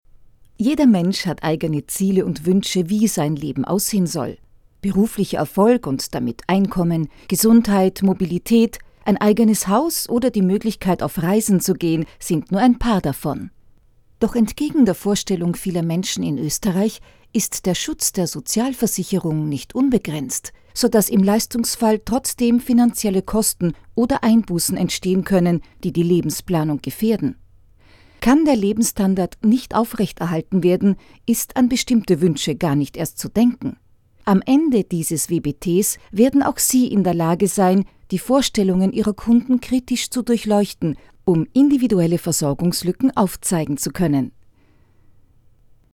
Sprechprobe: eLearning (Muttersprache):
Austrian voice over artist with professional studio. My voice is smooth, warm, friendly, solid, confident and trustable.